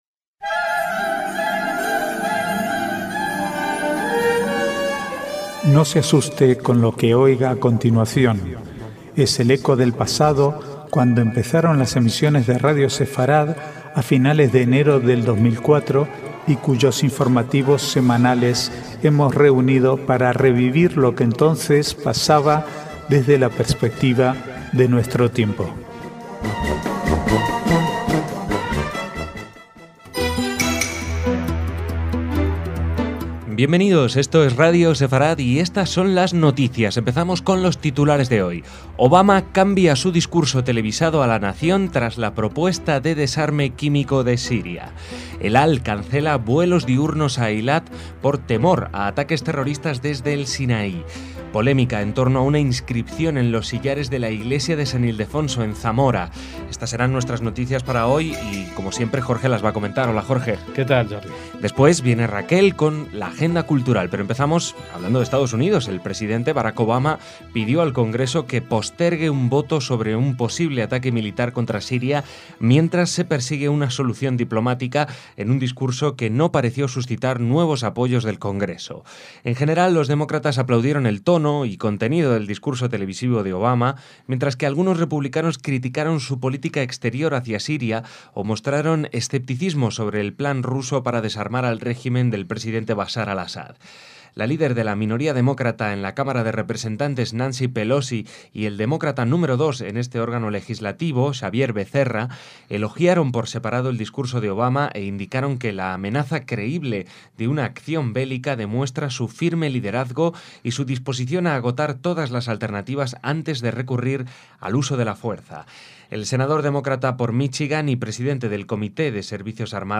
Archivo de noticias del 12 al 18/9/2013